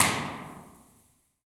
Tijuana Aqueduct Tunnel
Concrete, debris.
Download this impulse response (right click and “save as”)
TijuanaAqueductTunnel.wav